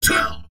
文件 文件历史 文件用途 全域文件用途 Enjo_atk_04_3.ogg （Ogg Vorbis声音文件，长度0.5秒，153 kbps，文件大小：9 KB） 源地址:地下城与勇士游戏语音 文件历史 点击某个日期/时间查看对应时刻的文件。